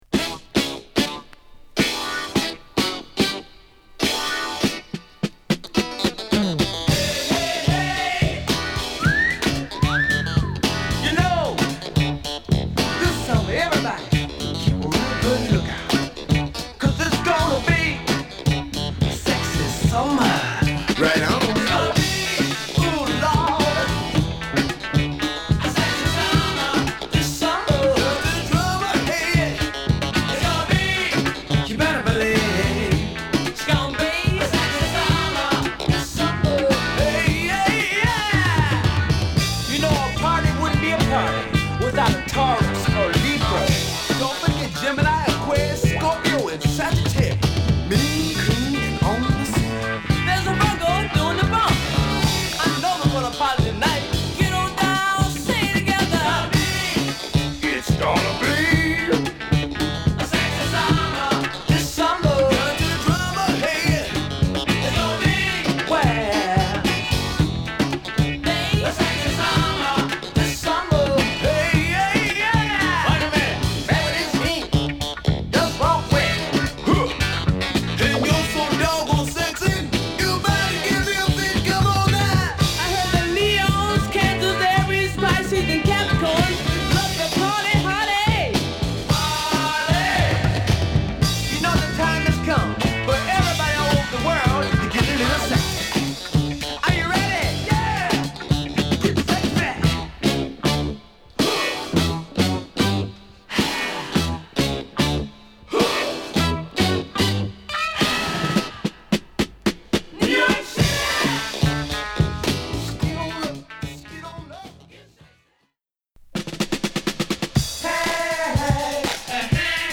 コチラも賑やかなファンク／ディスコのB